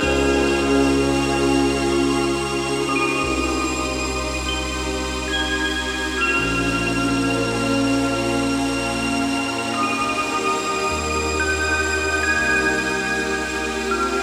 WAY UP 135 BPM - FUSION.wav